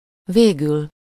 Ääntäminen
Ääntäminen Tuntematon aksentti: IPA: /ˈveːɡyl/ Haettu sana löytyi näillä lähdekielillä: unkari Käännös Ääninäyte Adverbit 1. finally US 2. lastly 3. ultimately 4. eventually 5. at last Esimerkit végül is after all